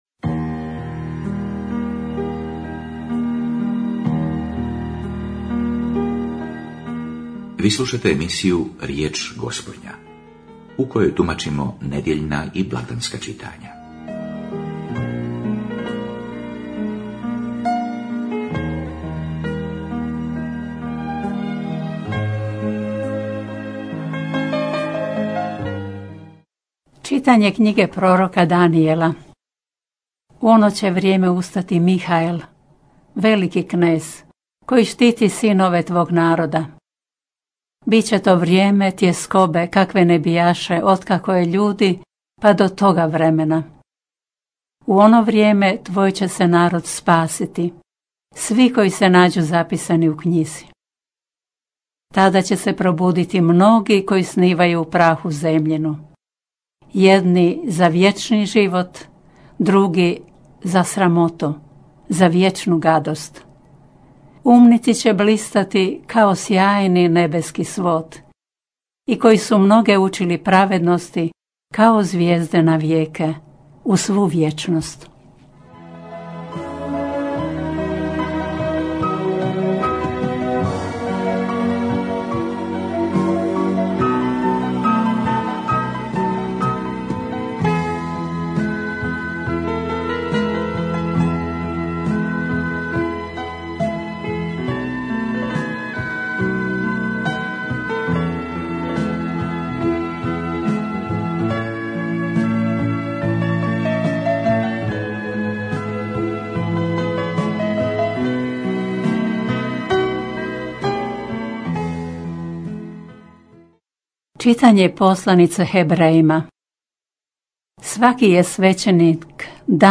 Riječ Gospodnja - homilija